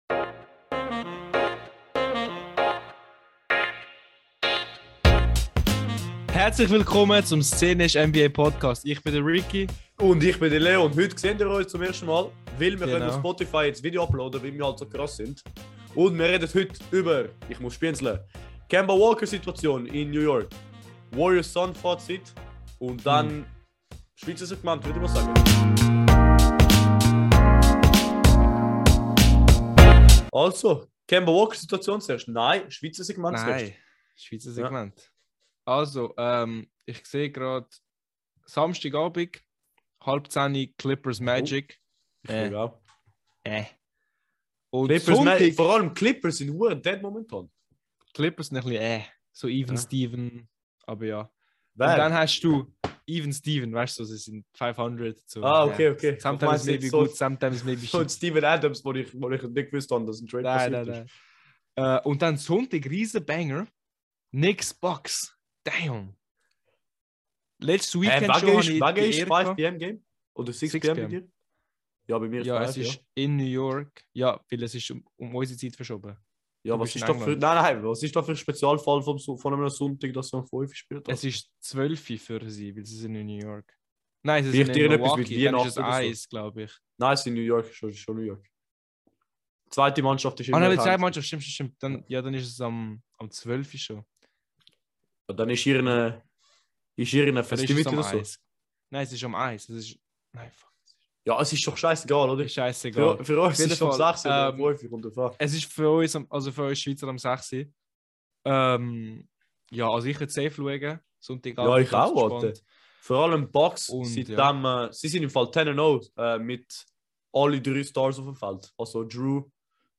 Zwei Jungs us Züri, zwei Mikros, nur NBA.